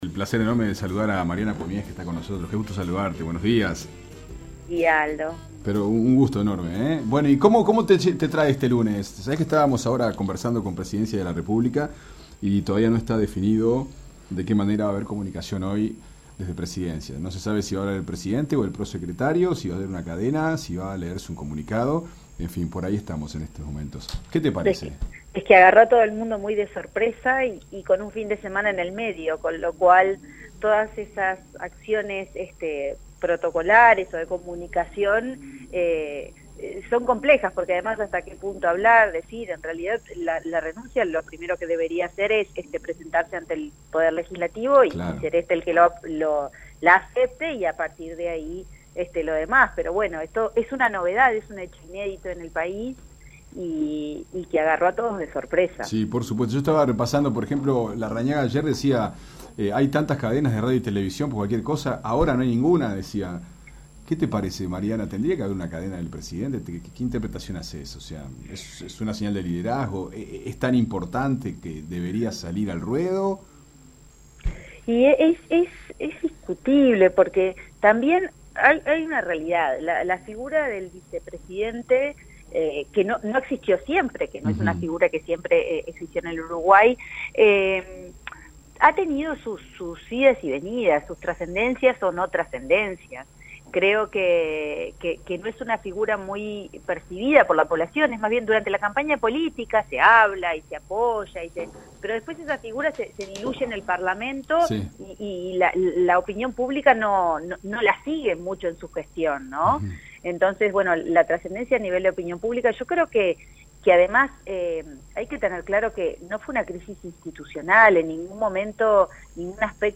Fuentes Confiables dialogó con la Socióloga